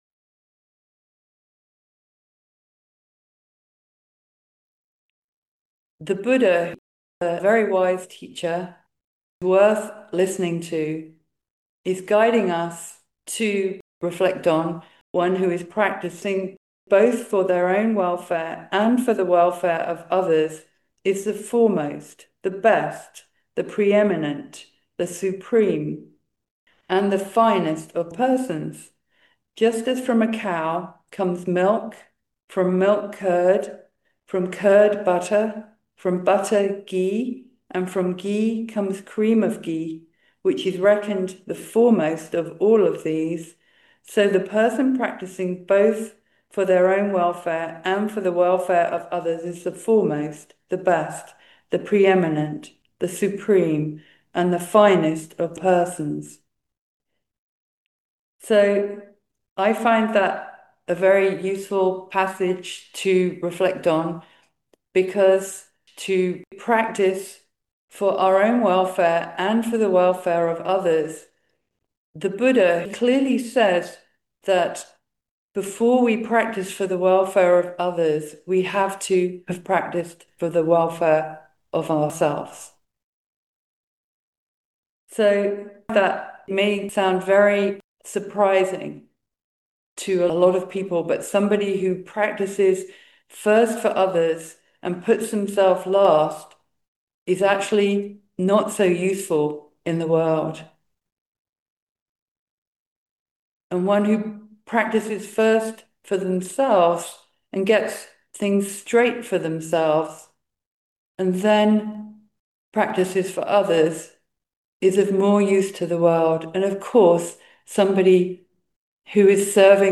Just so, caring for ourselves, we care for each other – sheltering in love, kindness, compassion and wisdom. A talk at the Toronto Theravada Buddhist Community, Nov. 15, 2025